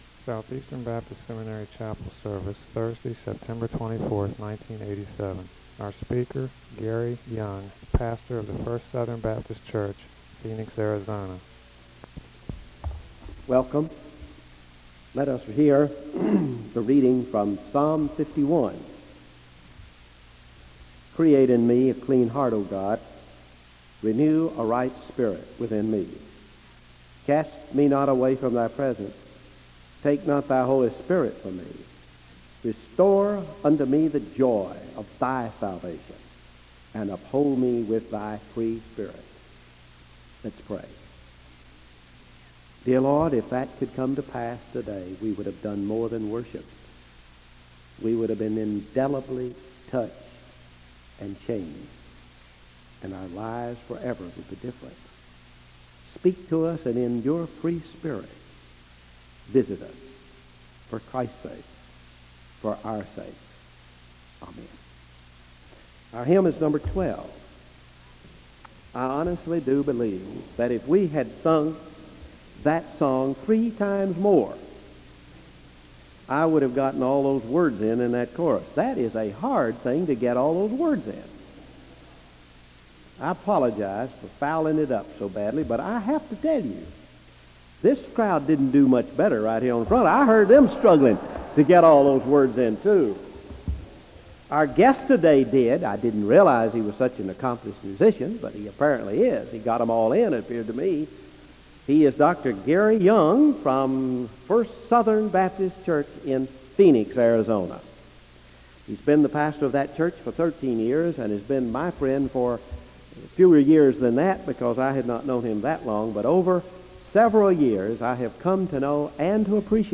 The service begins with a Scripture reading from Psalm 51 and a word of prayer (0:00-1:05).
Location Wake Forest (N.C.)